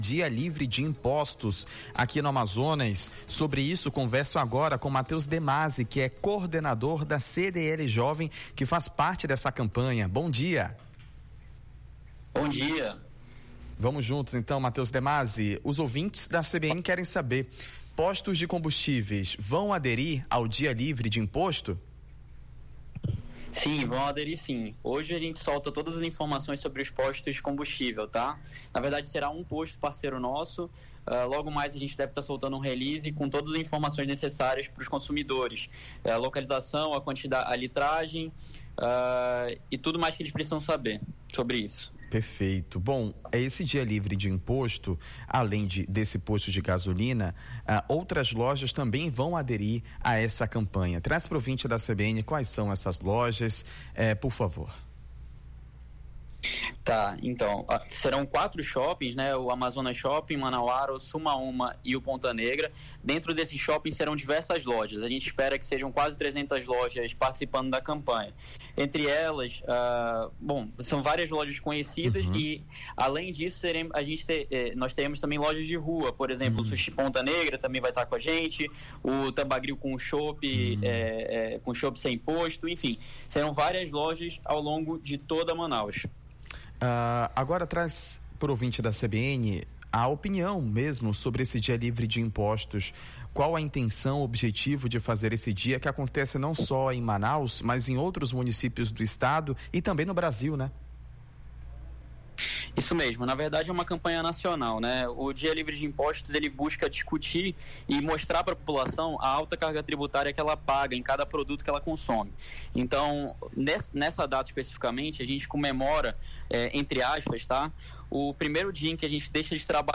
Manaus